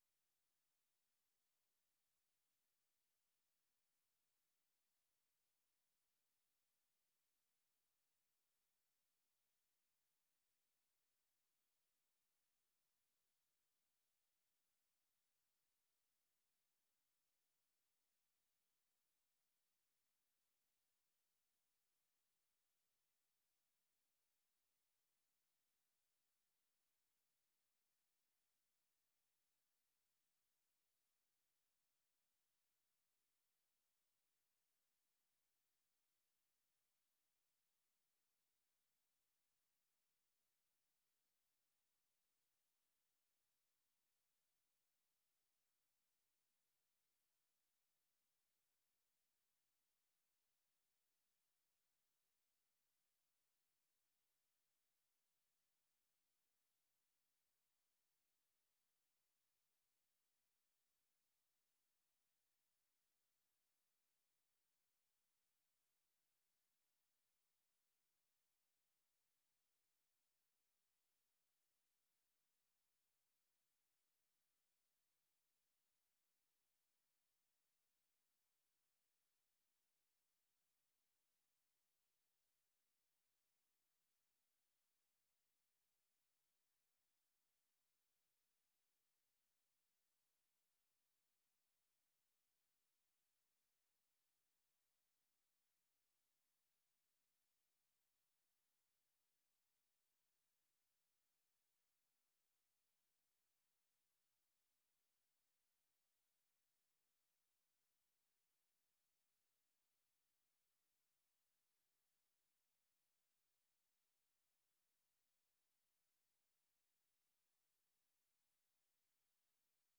White Git2.wav